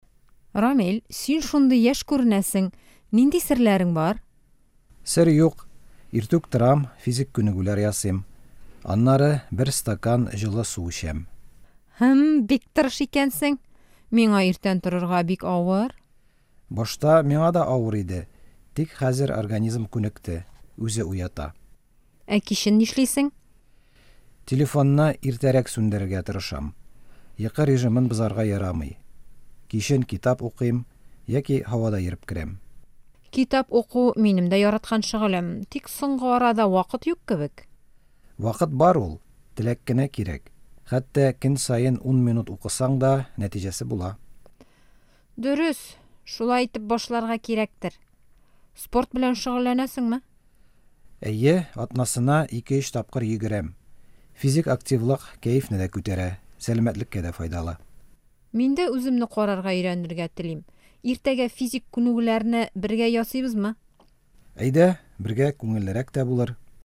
Начните с простого: выучите, как по-татарски сказать "делаю зарядку" или "ложусь спать вовремя". В новом уроке рубрики "Татарский на каждый день" говорим про полезные привычки.